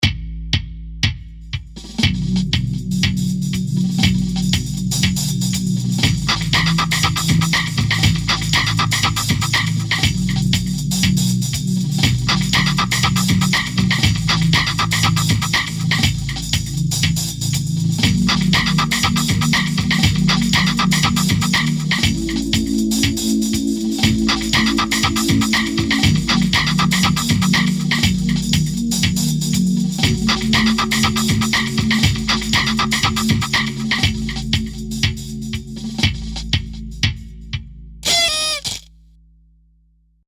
(Note: The song has a very low bass line, which might not be audible on some computer speakers.)